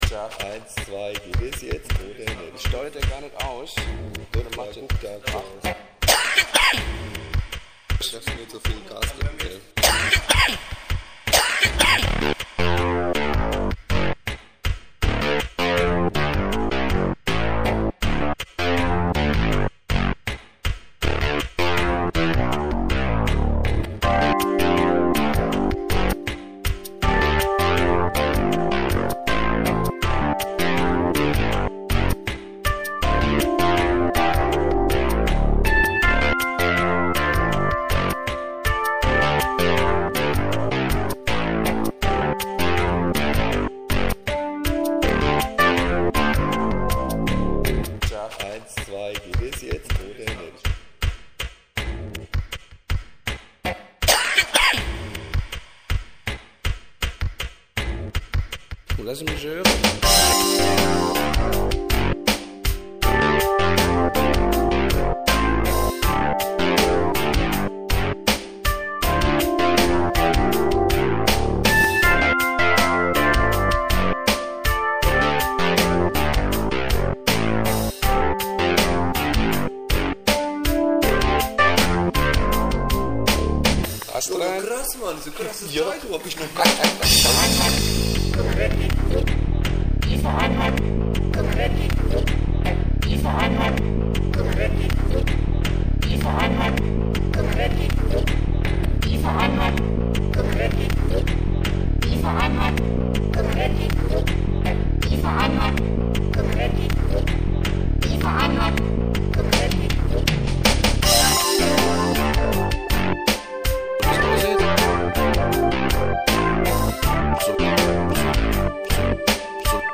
10 Instrumentalstücke, von Jazz Rock bis New Age.